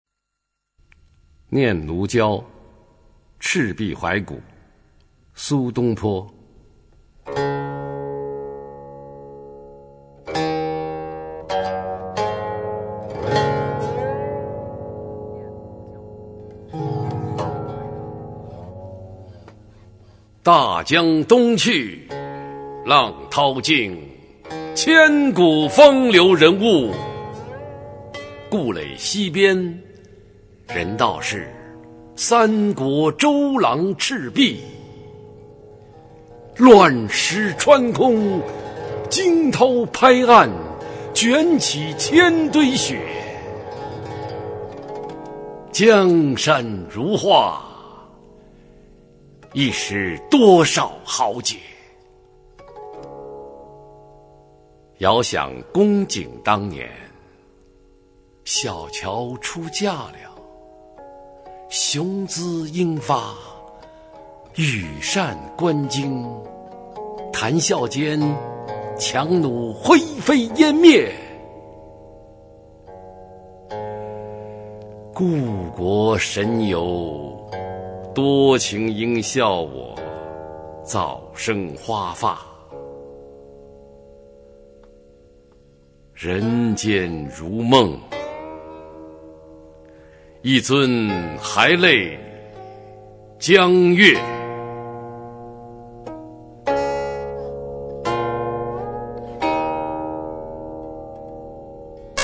《念奴娇·赤壁怀古》原文和译文（含赏析、朗读）